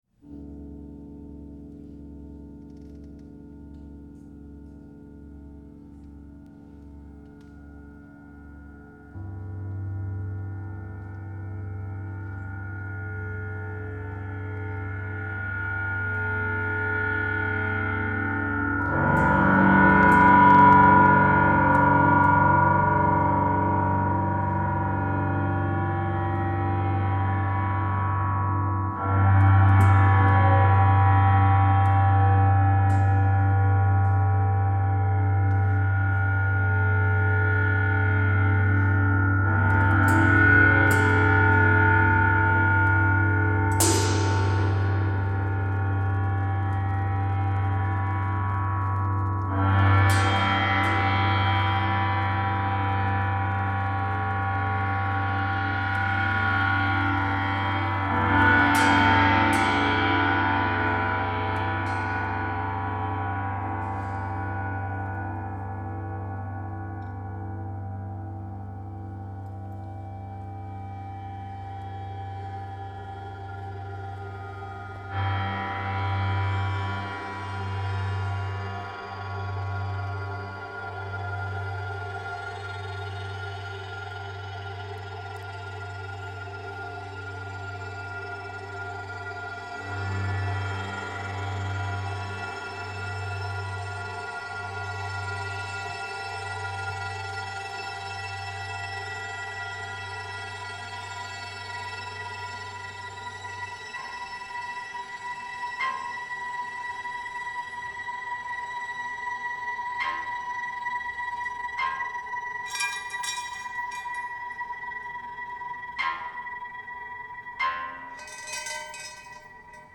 Recording of first performance